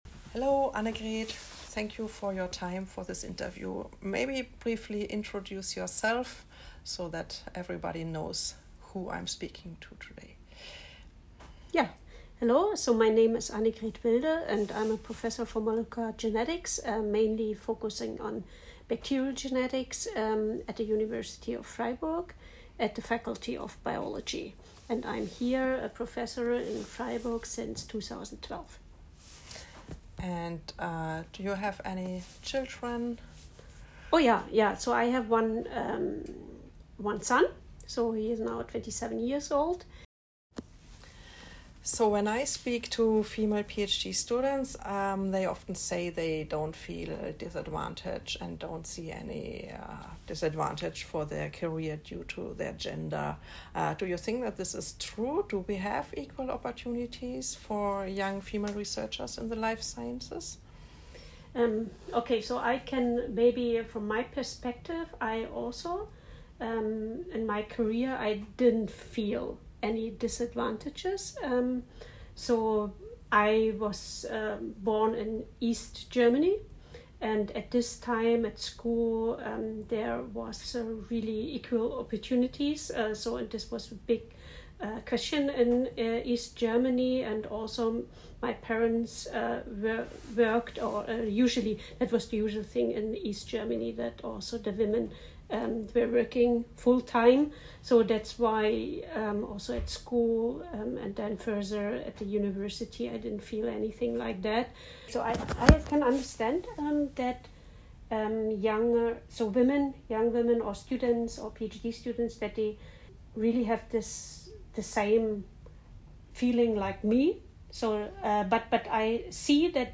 interview-womens-day-2025